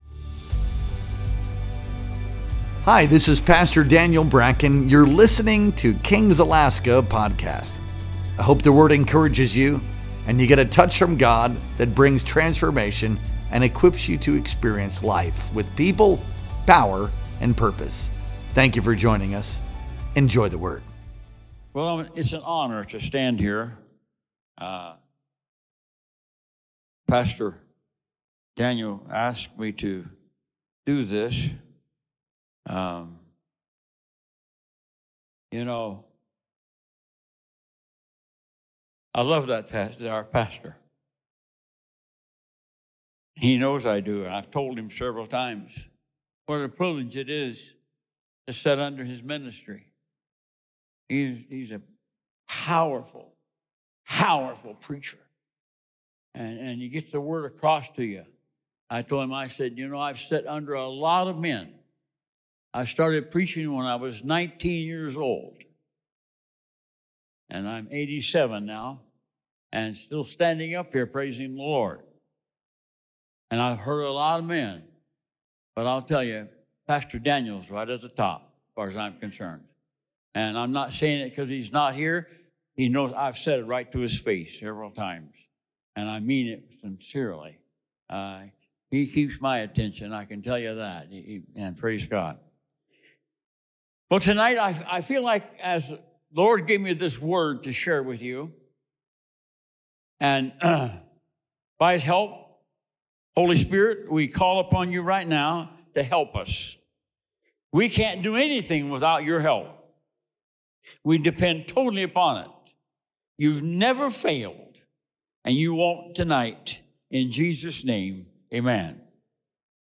Our Wednesday Night Worship Experience streamed live on May 28th, 2025.